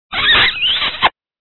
kiss.wav